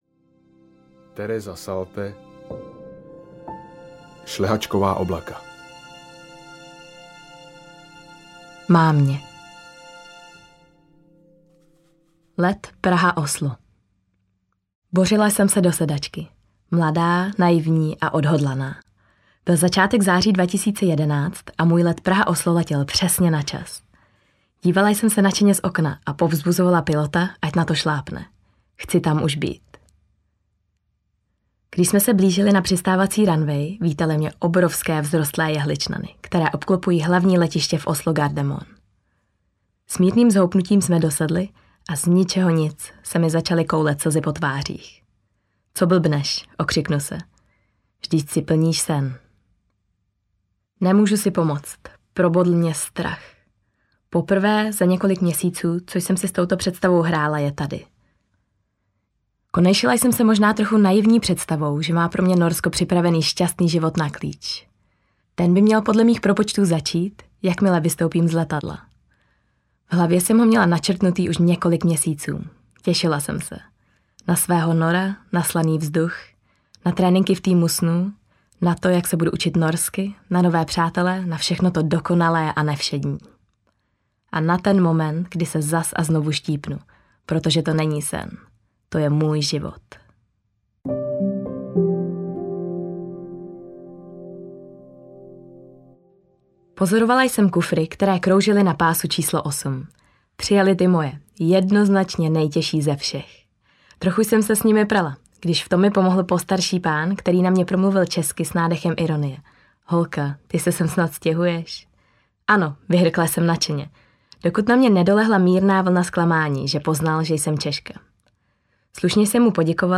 Šlehačková oblaka audiokniha
Ukázka z knihy